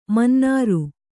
♪ mannāru